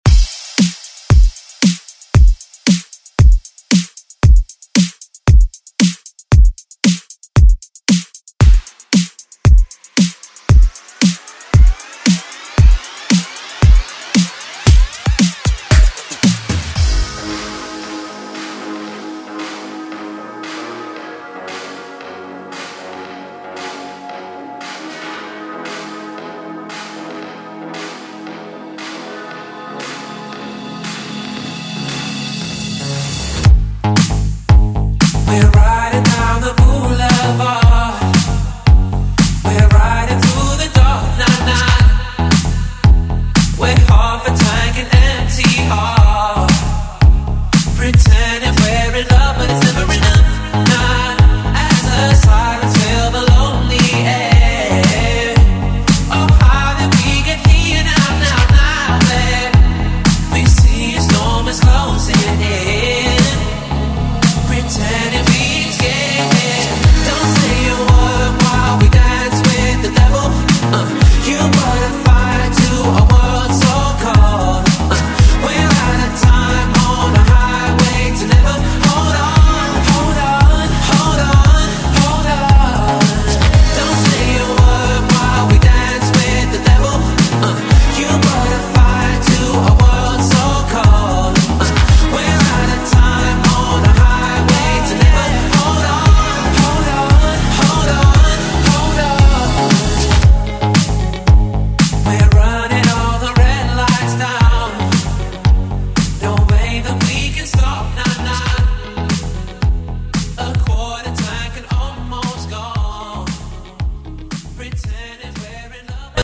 Genre: 70's
Clean BPM: 124 Time